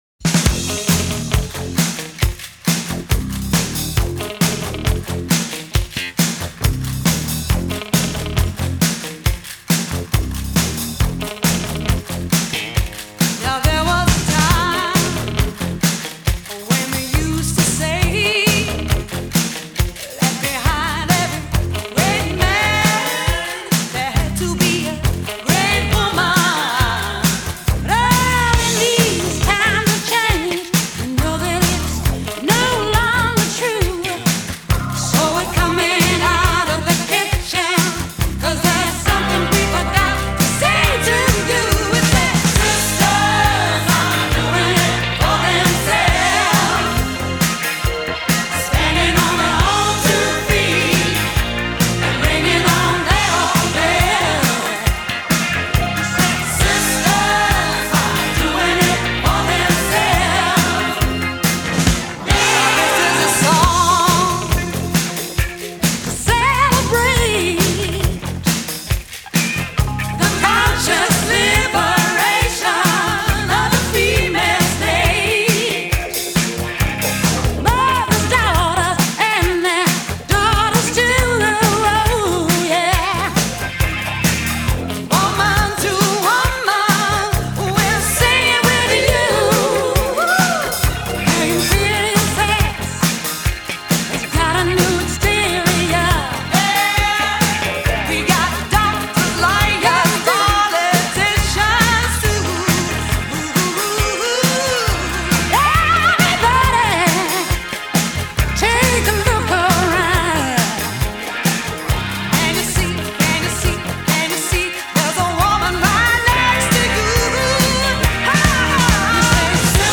синти-поп-дуэт